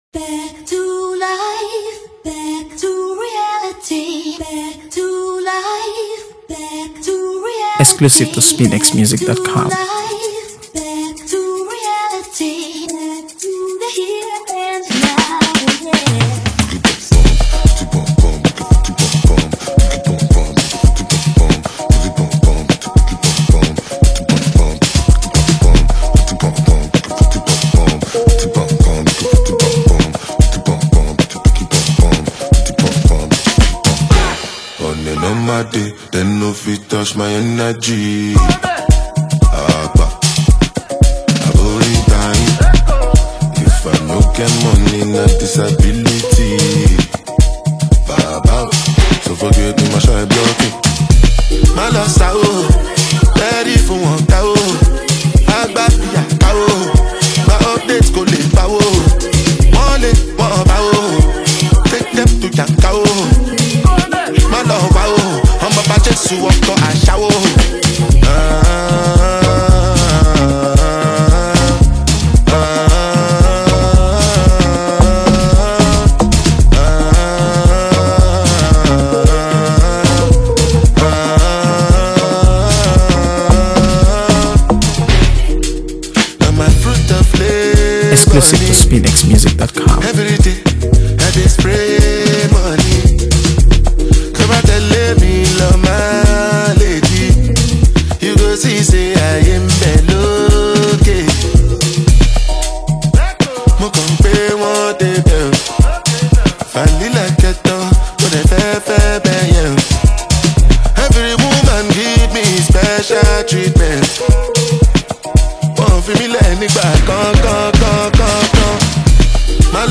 AfroBeats | AfroBeats songs
Award-winning Nigerian singer and Afro-fusion superstar
a smooth blend of Afrobeat, dancehall, and global sounds.